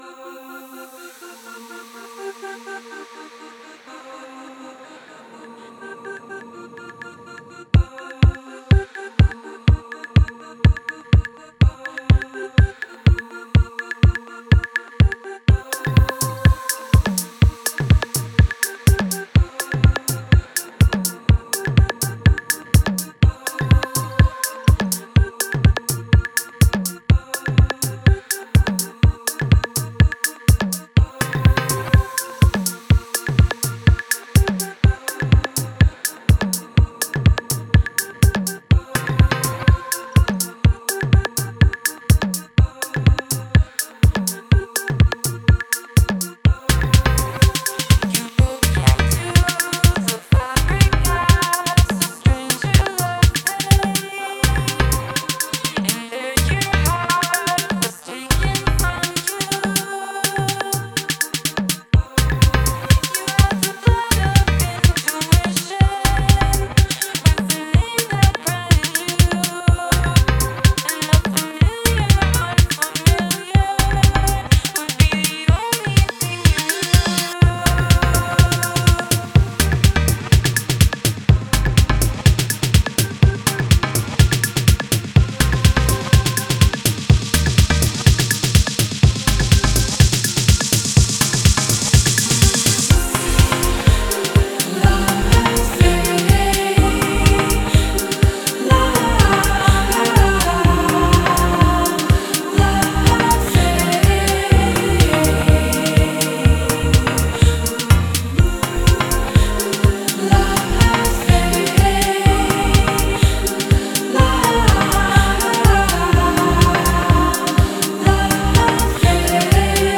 with tick tock beats and android synths